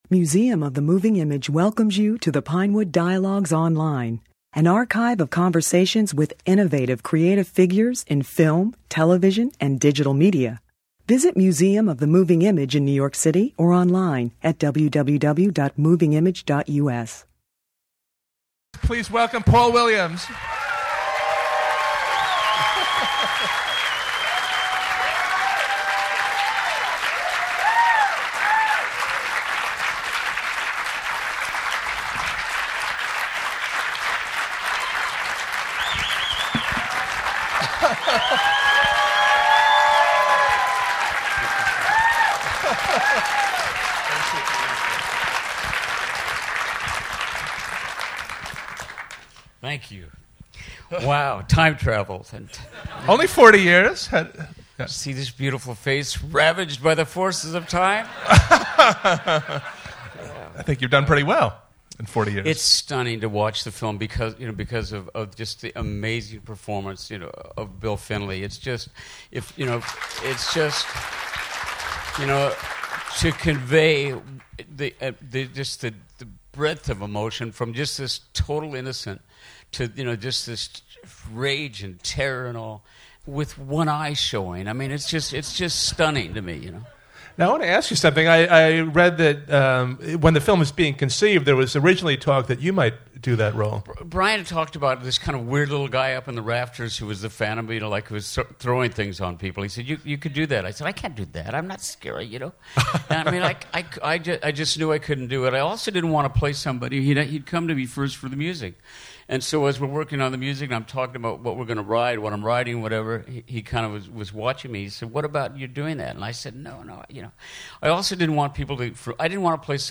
Williams was honored with a weekend retrospective at Museum of the Moving Image. This discussion followed the screening of Phantom of the Paradise .